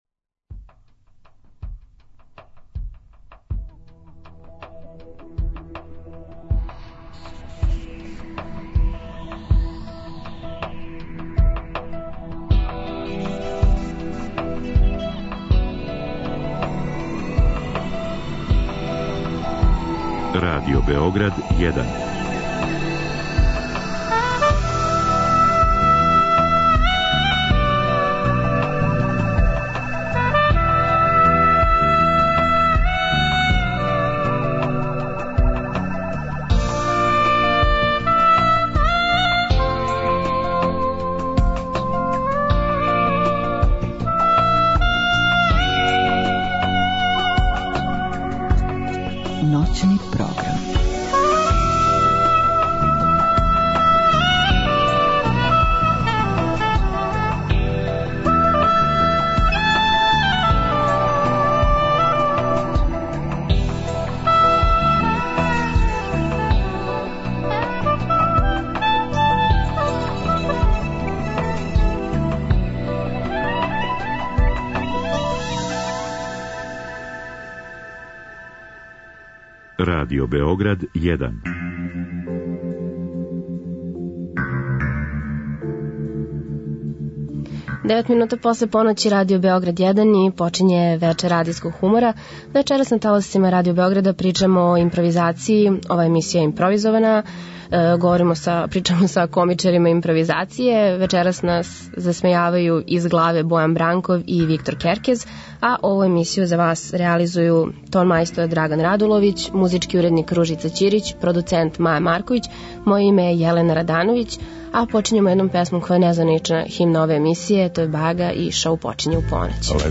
Импровизована емисија са комичарима импровизације који импровизују.